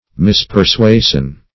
Search Result for " mispersuasion" : The Collaborative International Dictionary of English v.0.48: Mispersuasion \Mis`per*sua"sion\, n. A false persuasion; wrong notion or opinion.
mispersuasion.mp3